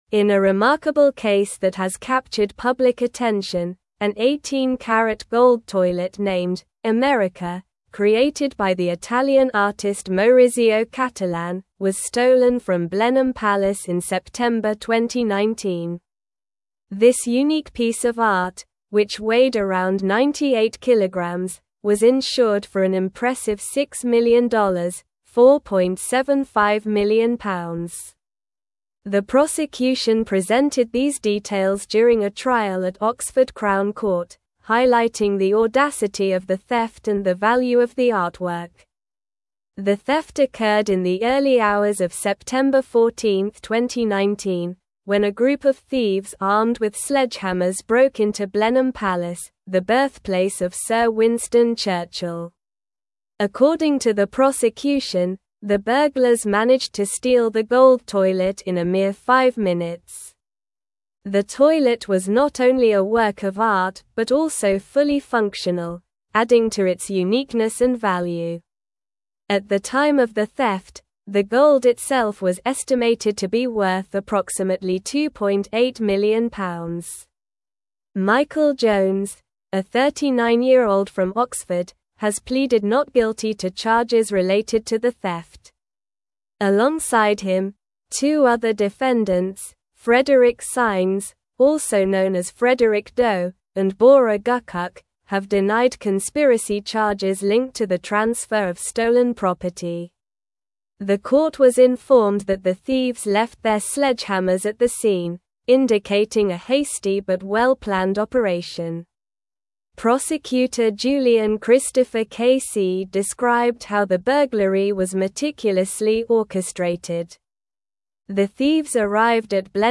Slow
English-Newsroom-Advanced-SLOW-Reading-The-Great-Gold-Toilet-Heist-at-Blenheim-Palace.mp3